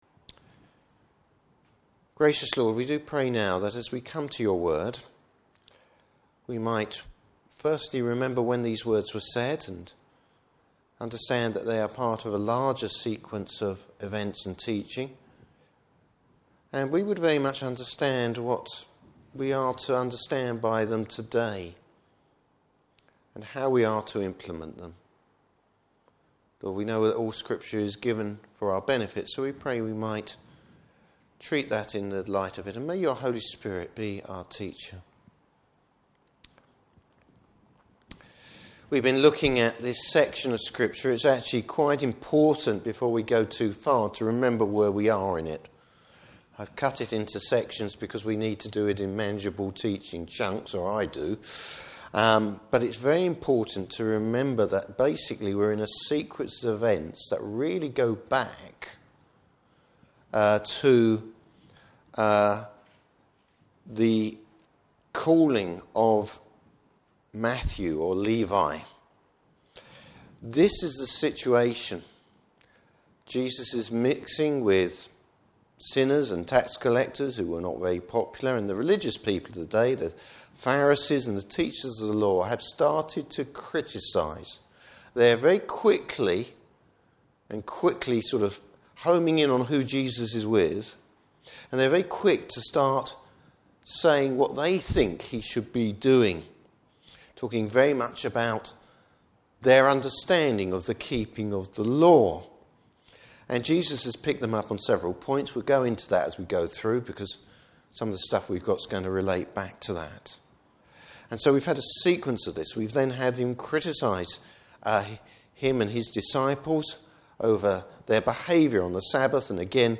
Service Type: Morning Service How do we understand Jesus’ teaching is it realistic, what does it mean?